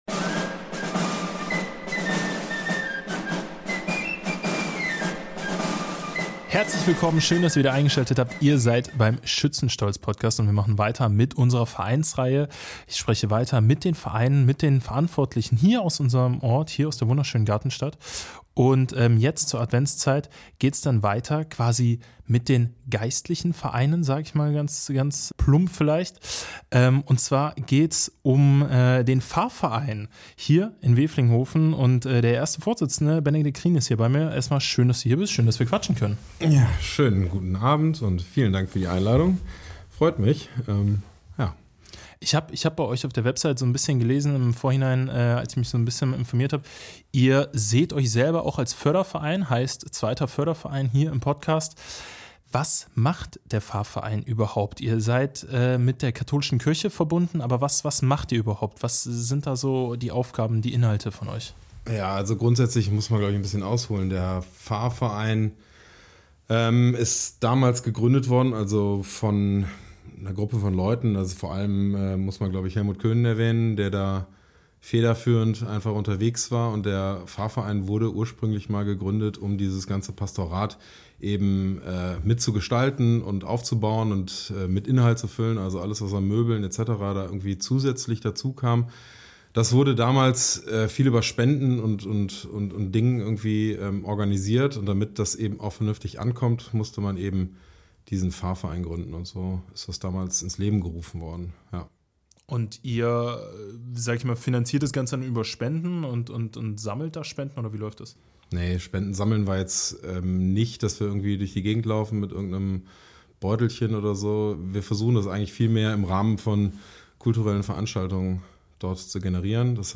Ein ehrliches Gespräch über Engagement, Zusammenhalt und die Parallelen zwischen Kirche, Kultur und Schützenwesen.